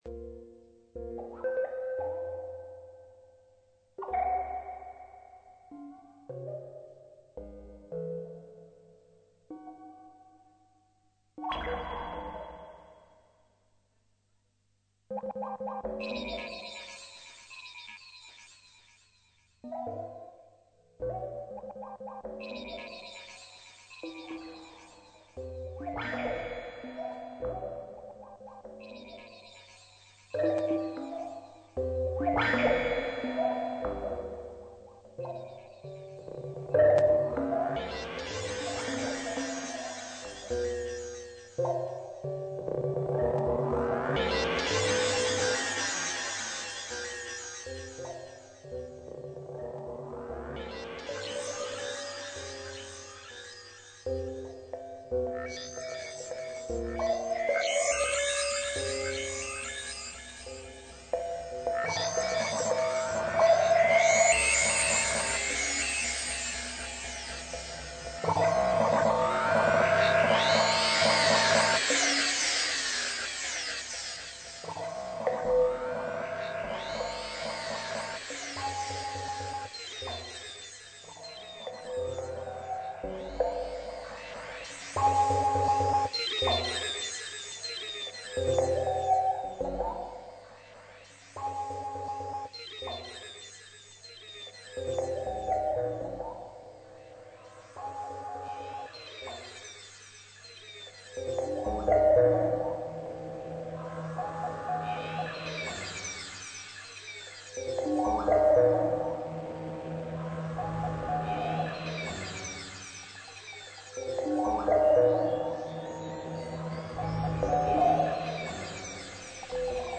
v veliki dvorani Cirkulacije 2.
glas